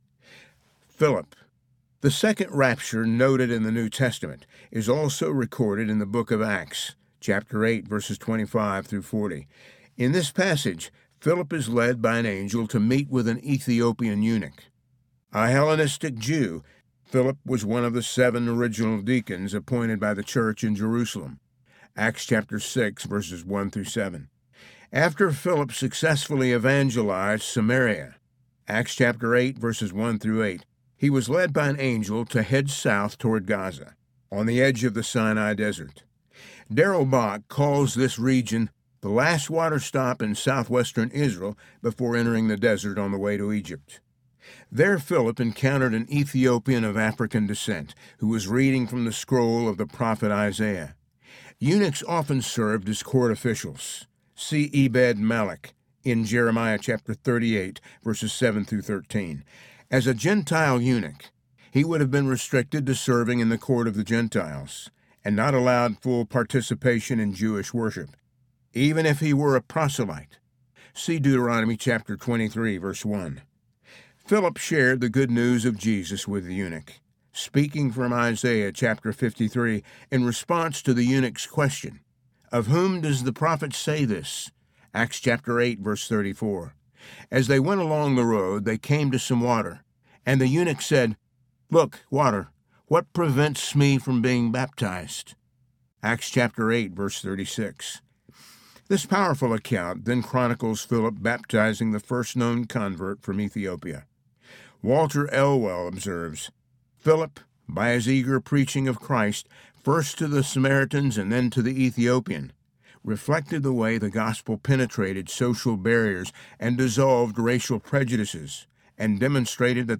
Can We Still Believe in the Rapture? Audiobook
Narrator
6.32 Hrs. – Unabridged